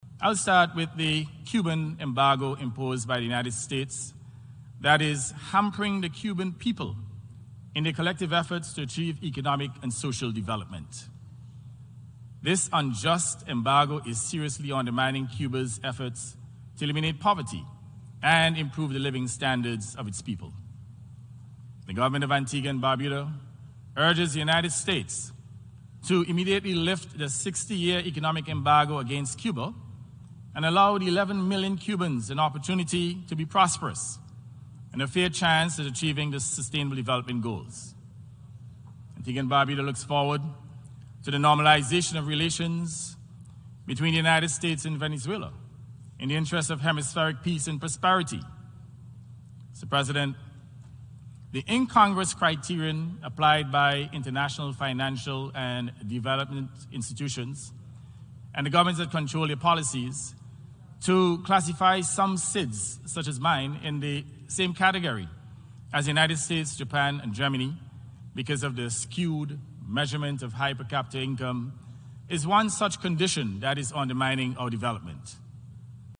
He told the United National General Assembly last week that the unjust embargo is seriously undermining Cuba’s efforts to eliminate poverty and improve the living standards of its people.
GASTON-SPEAKS-ON-CUBA-UN.mp3